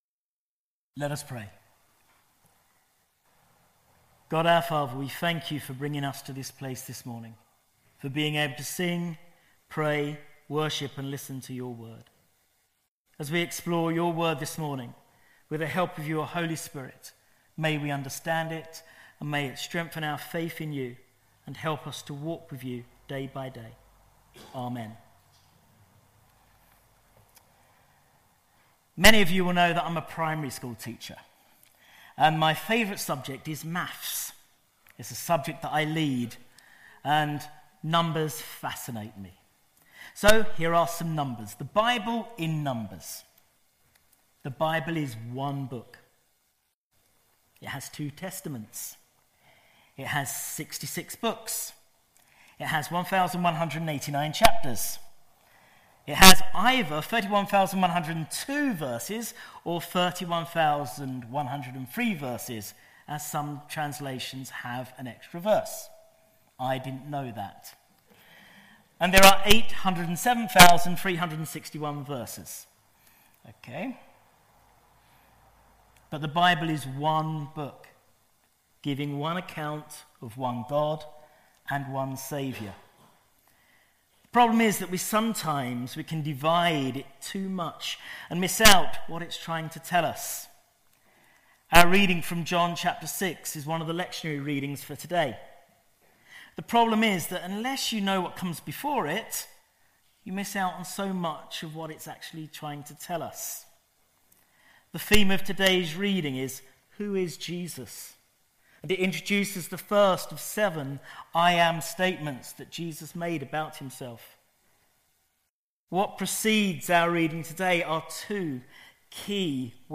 The talk is also available as an audio file.